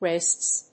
/rɪsts(米国英語)/